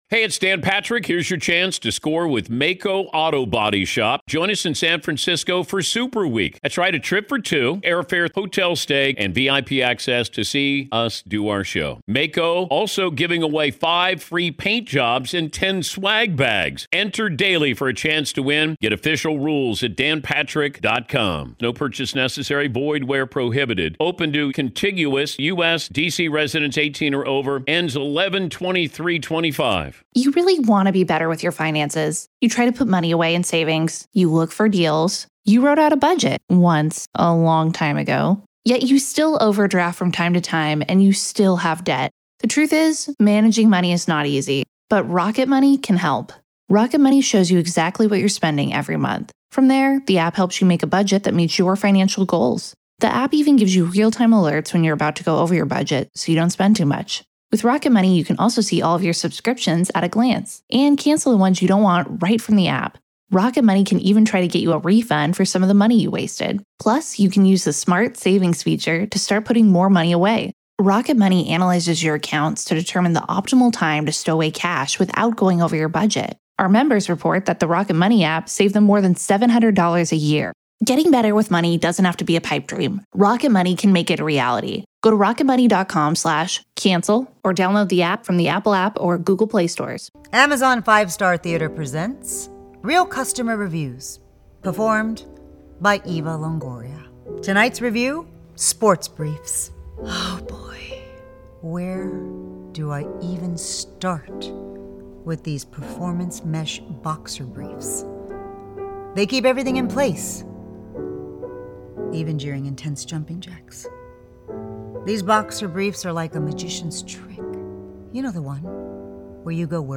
So what's really going on here? In this interview